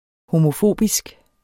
Udtale [ homoˈfoˀbisg ]